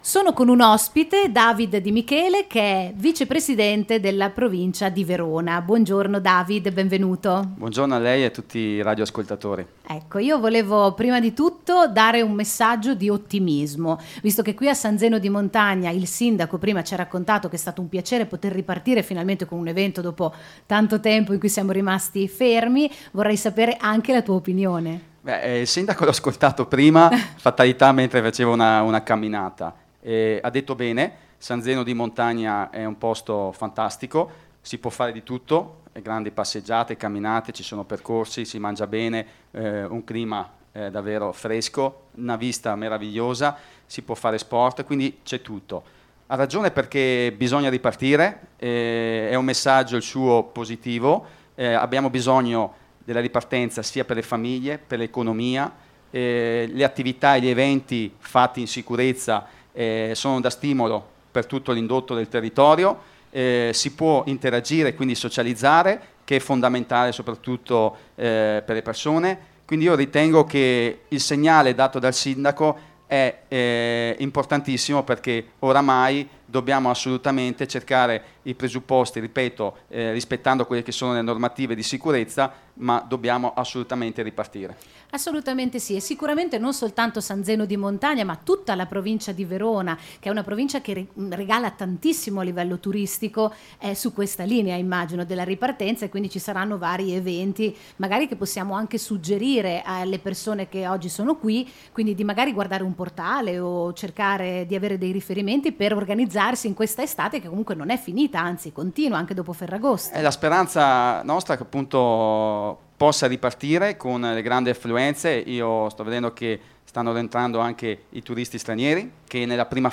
Lo studio mobile di Radio Pico domenica 9 agosto ha fatto tappa a San Zeno di Montagna, in provincia di Verona.
collegamenti in diretta e interviste
DAVID-DI-MICHELE-VICEPRESIDENTE-PROVINCIA-VERONA.mp3